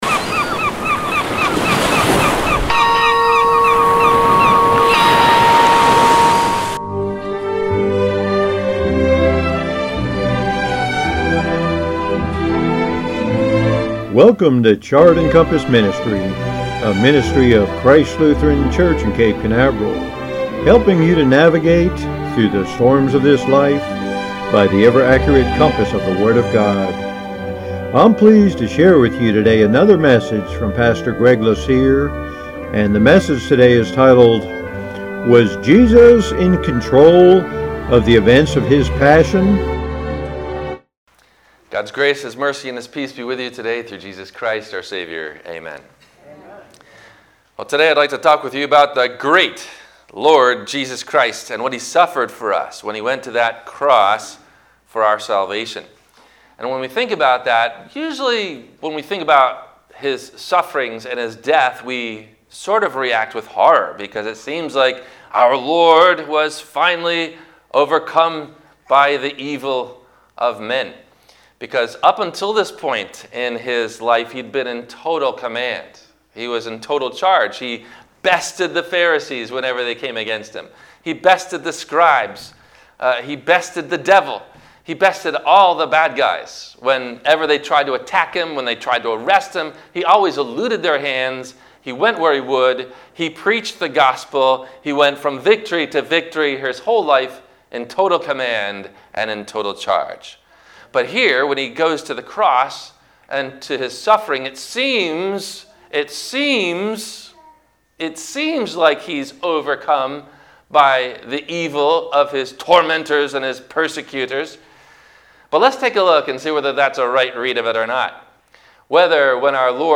Was Jesus in Control of the Events of His Passion? – WMIE Radio Sermon – April 05 2021